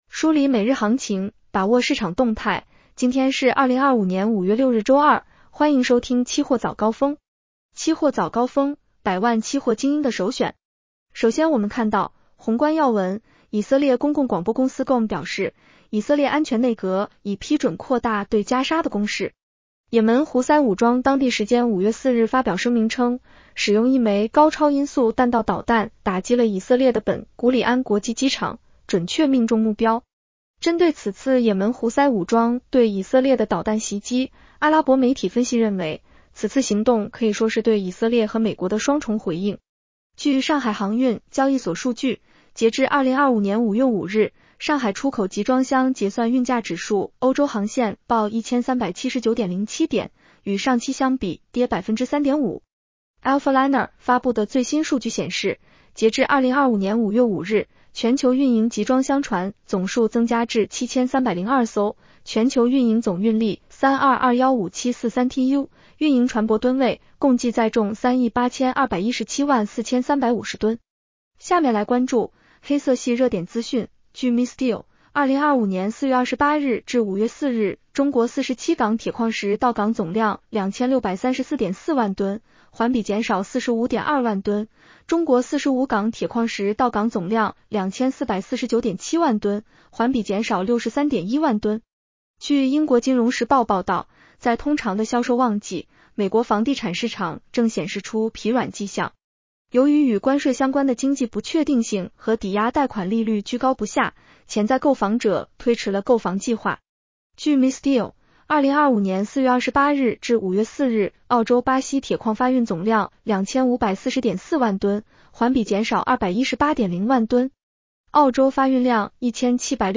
女声普通话版 下载mp3